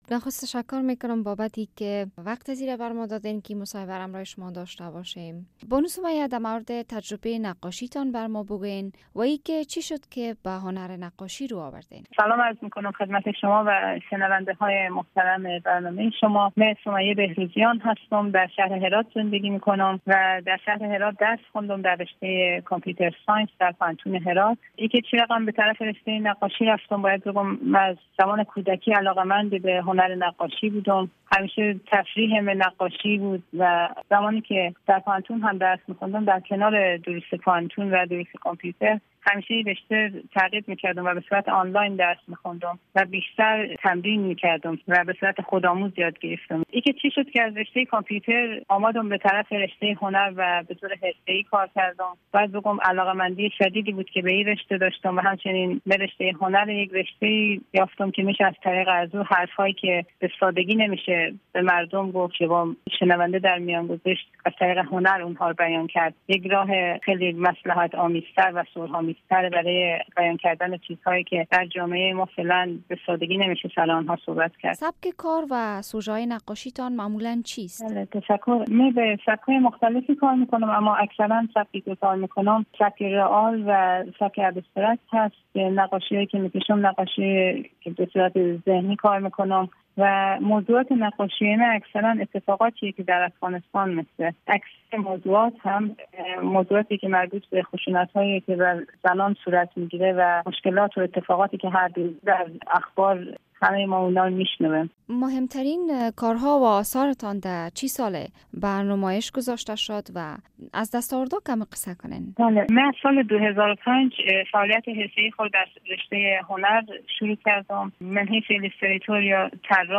شرح کامل مصاحبه